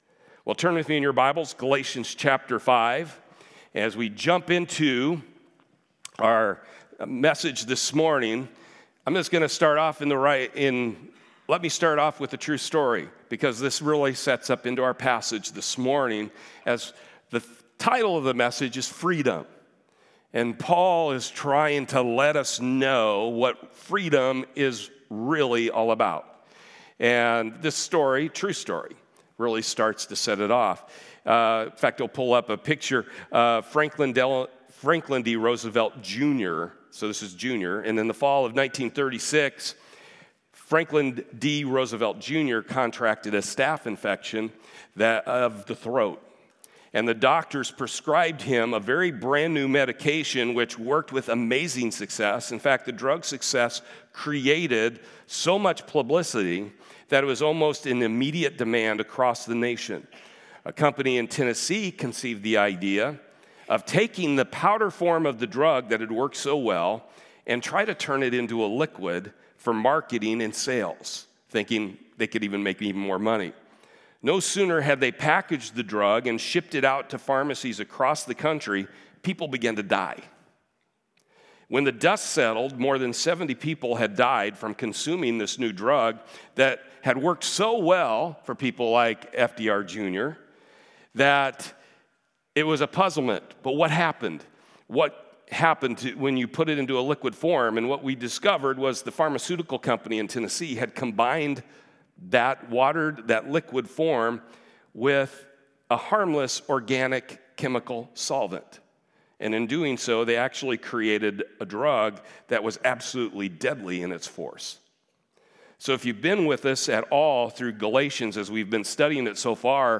Series: The Book of Galatians Service Type: Sunday